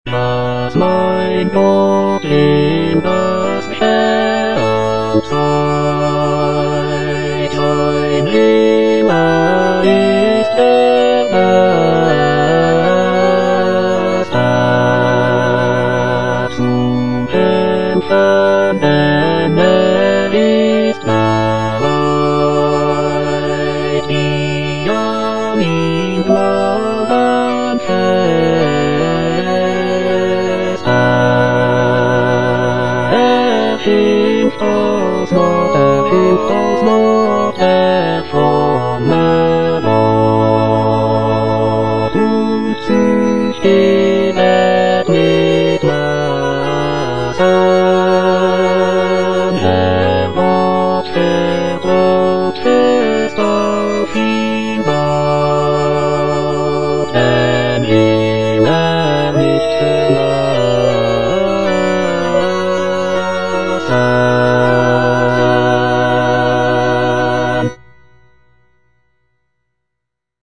The music is deeply expressive and reflective, conveying a sense of devotion and contemplation.